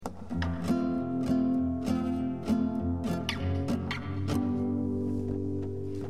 Sometimes Chromaticism is used to switch between diatonic chords.
Chromaticism